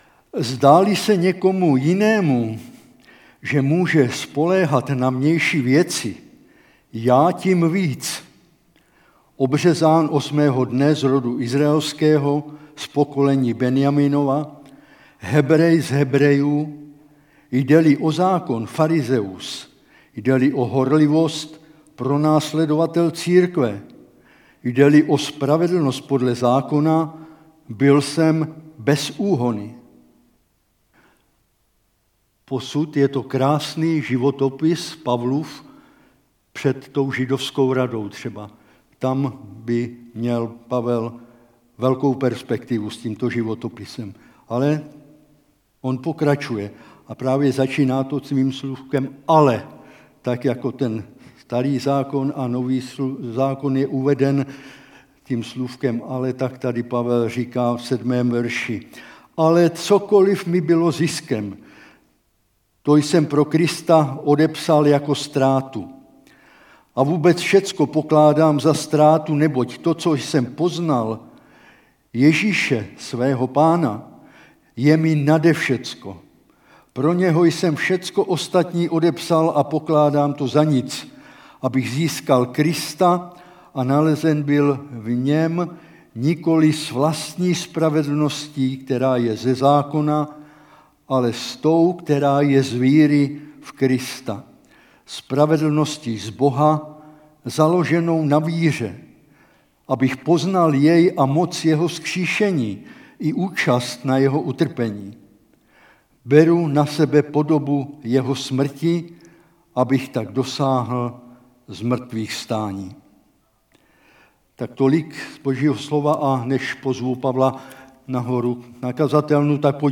Kategorie: Nedělní bohoslužby